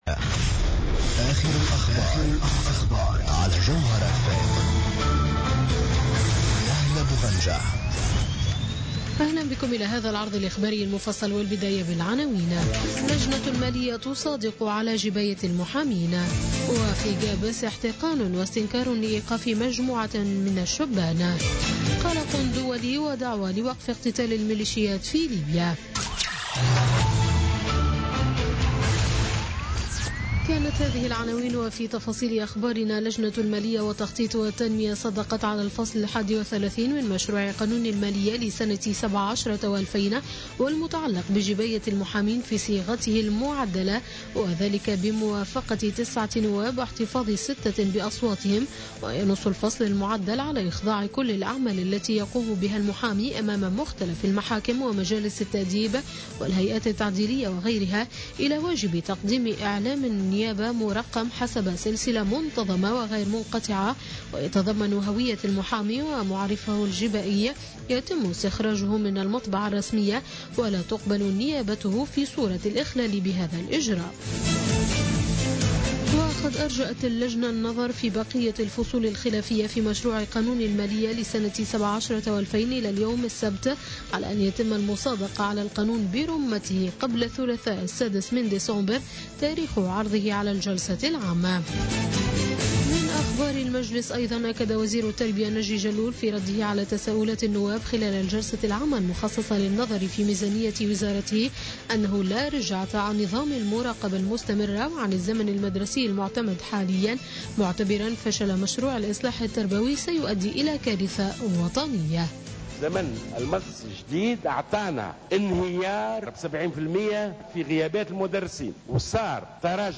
Journal Info 00h00 du samedi 3 Décembre 2016